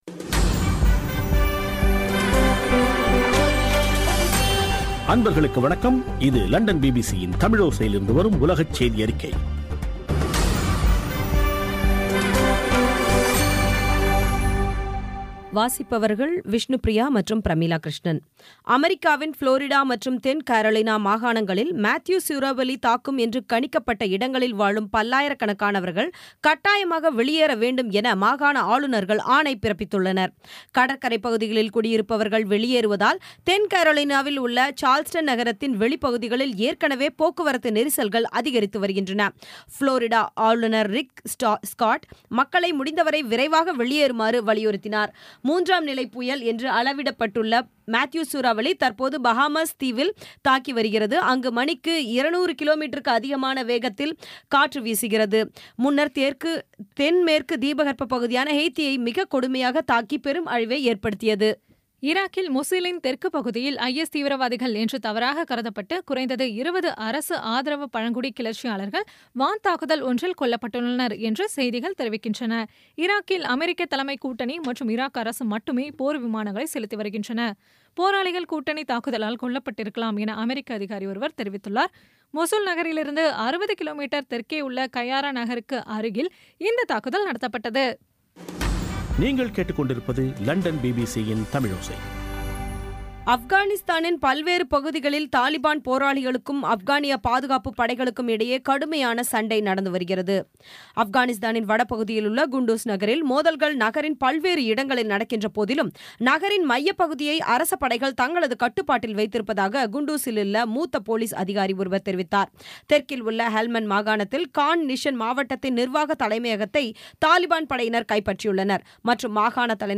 பி பி சி தமிழோசை செய்தியறிக்கை (05/10/2016)